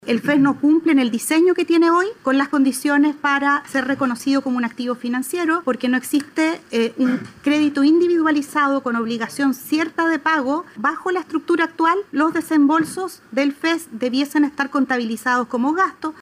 Paula Benavides, presidenta del CFA, dijo que la situación financiera del CAE muestra un desequilibrio estructural importante y sentenció que el FES no cumple en su diseño actual, las condiciones para generar ingresos o ser un activo financiero.